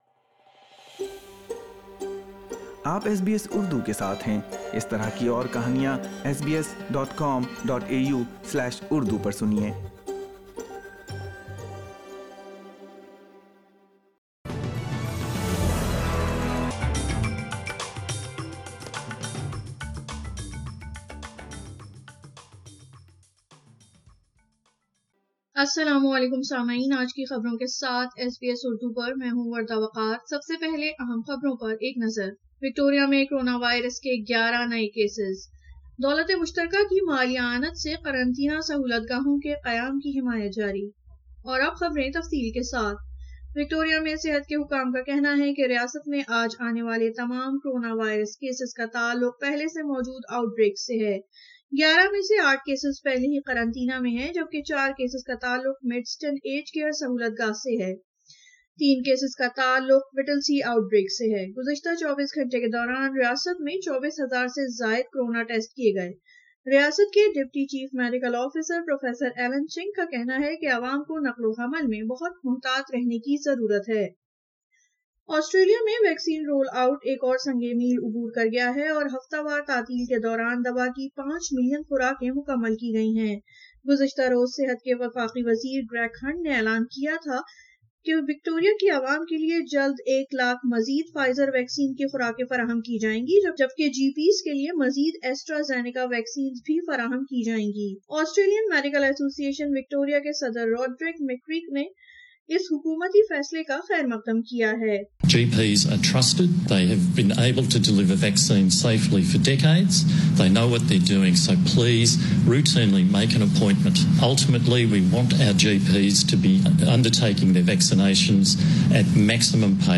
SBS Urdu News 07 June 2021